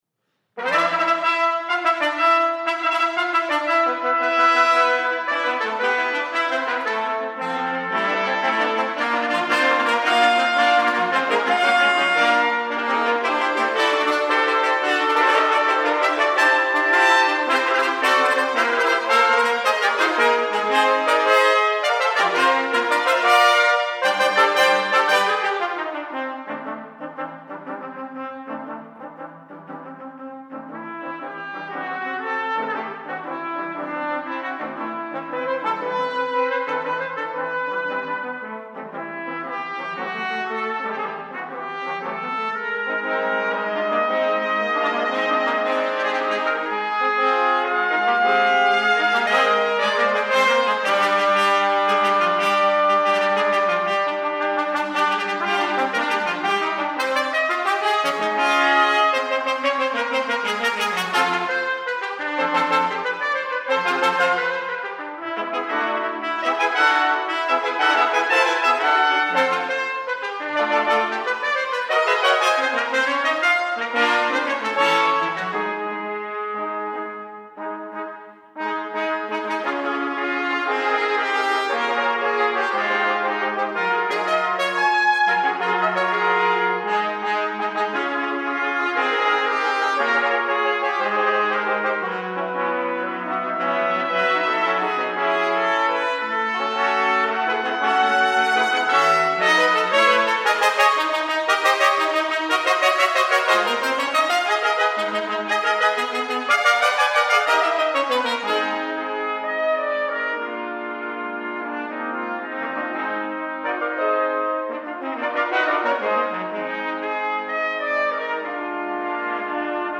Number of Trumpets: 5
Key: Bb Major concert
A rather striking and very dramatic work for five trumpets